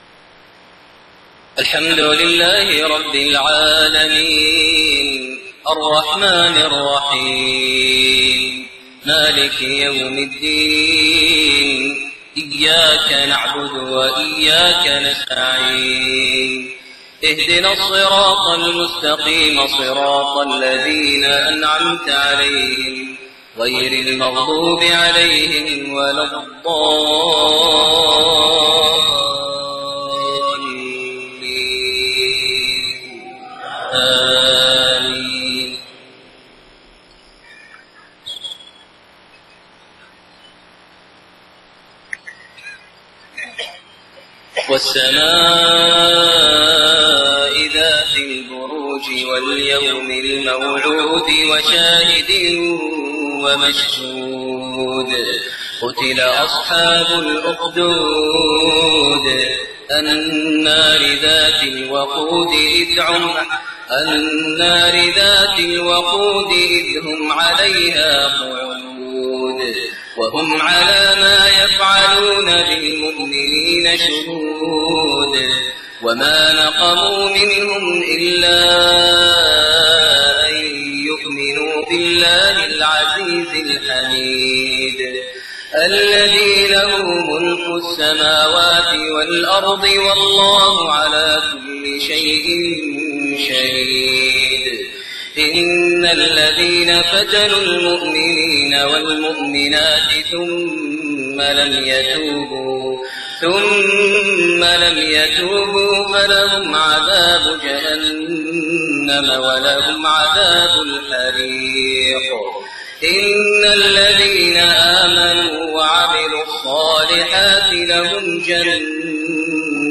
Maghrib prayer from Surat Al-Burooj > 1429 H > Prayers - Maher Almuaiqly Recitations